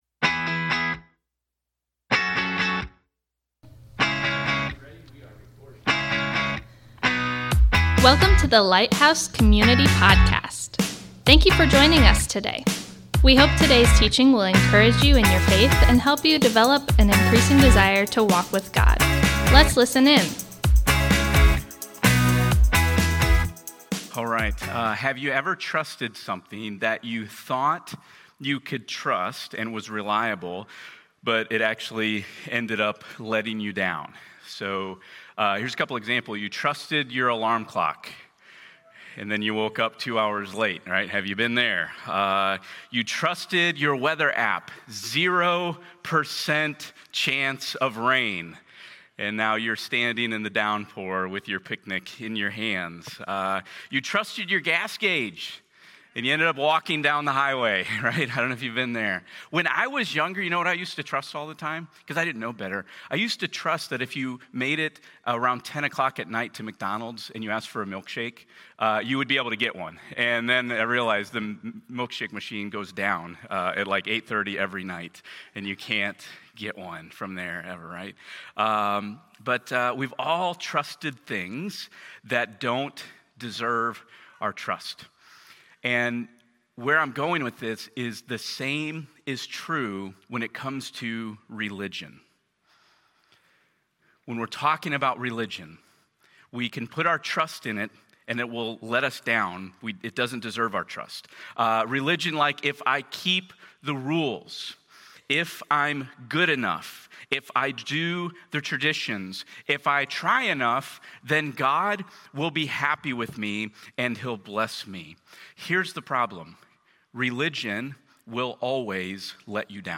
Thank you for joining us today as we come together to worship! This morning, we’re kicking off our new series, Missed It, where we’ll be exploring the the concept of escaping religion to find genuine faith. Today, we’re diving into Matthew 12:1–14 to answer an important question: What is the Sabbath, and what does it mean for us as Christians today?